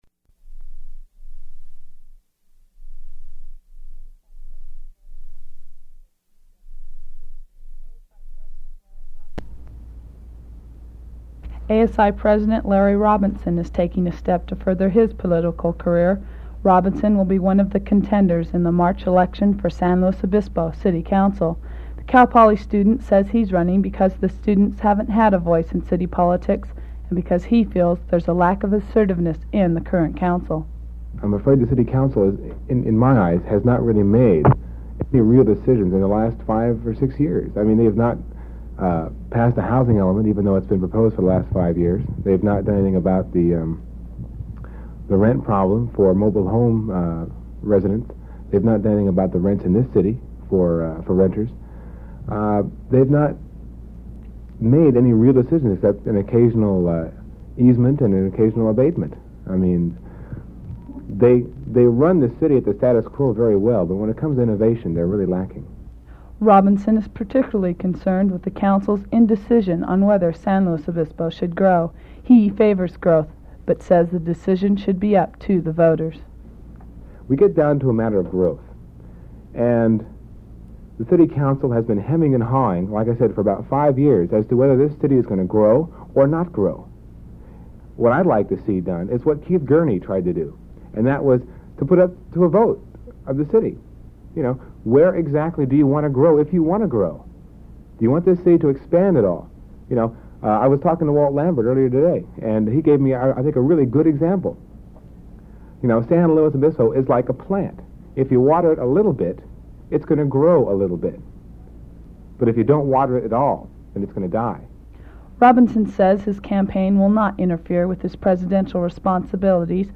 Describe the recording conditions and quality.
[recording cuts off]